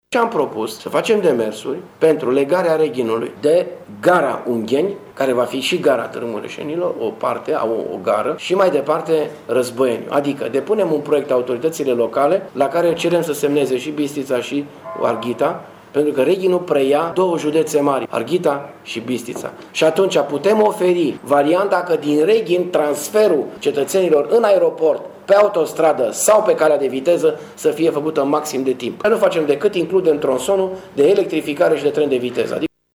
Dorin Florea a propus si electrificarea liniei feroviare Reghin-Ungheni: